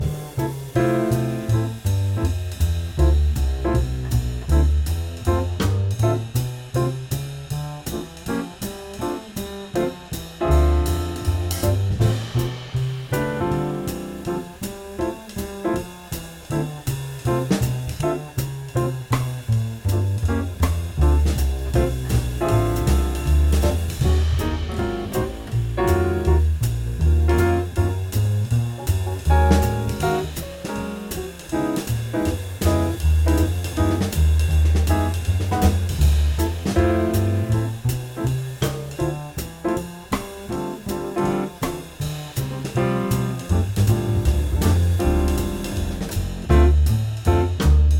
Playbacks Guitare
(Band In A Box)